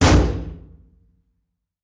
Звуки выбивания двери
Звук удара по металлической двери